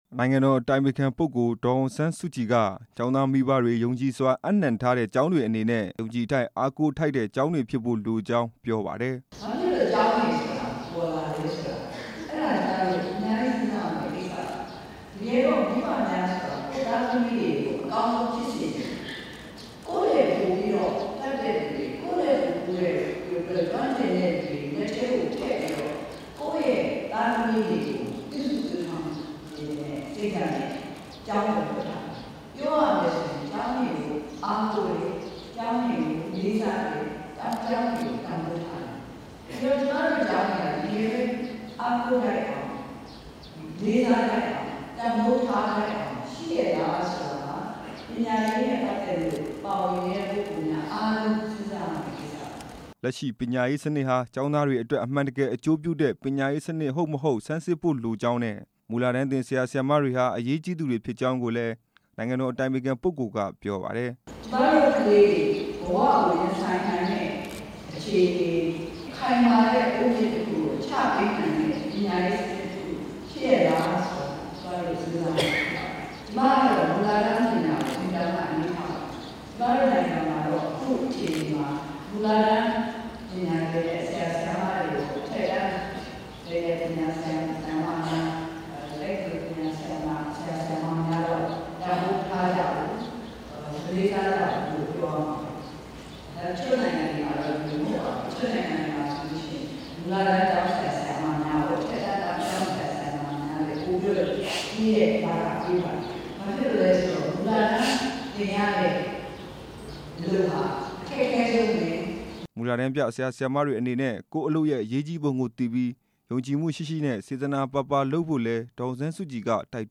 နေပြည်တော် အားကစားရွာမှာကျင်းပတဲ့ အခြေခံပညာအဆင့် ပညာရေးမြှင့်တင်ပွဲ အခမ်းအနားကို နိုင်ငံတော် အတိုင်ပင်ခံပုဂ္ဂိုလ် ဒေါ်အောင်ဆန်းစုကြည် တက်ရောက် မိန့်ခွန်းပြောကြားခဲ့ပါတယ်။